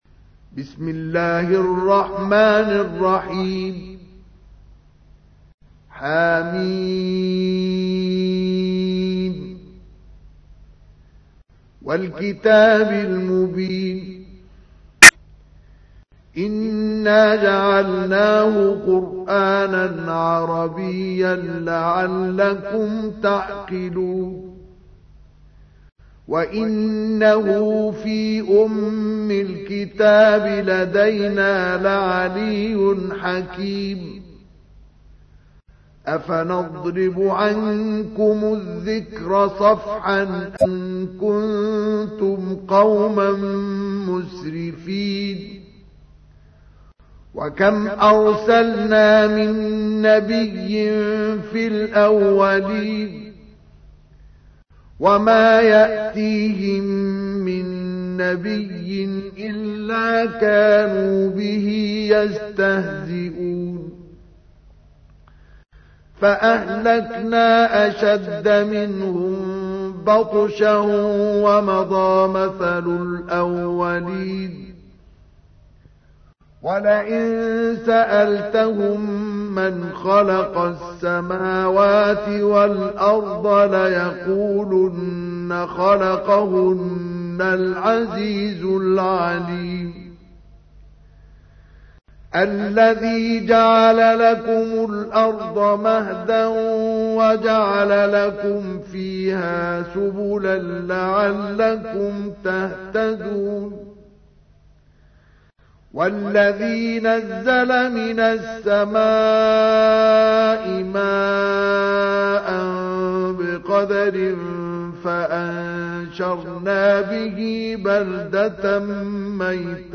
تحميل : 43. سورة الزخرف / القارئ مصطفى اسماعيل / القرآن الكريم / موقع يا حسين